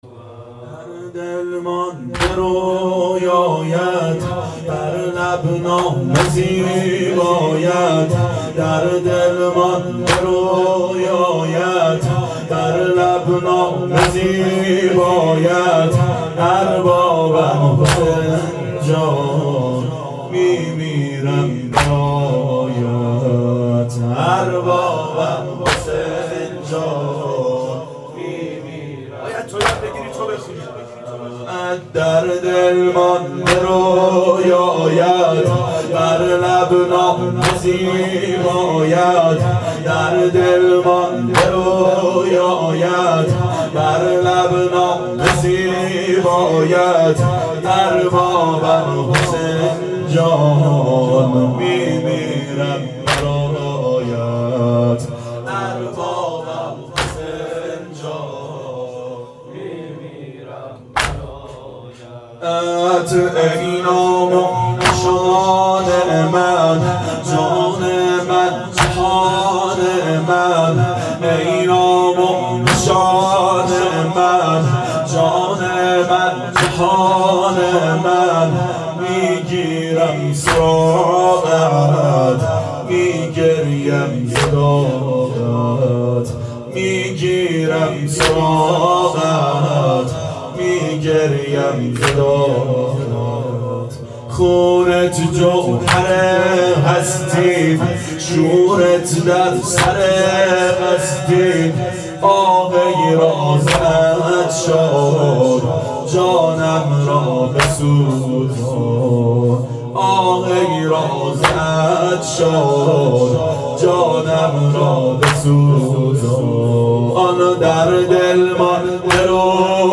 • شب اربعین 92 هیأت عاشقان اباالفضل علیه السلام منارجنبان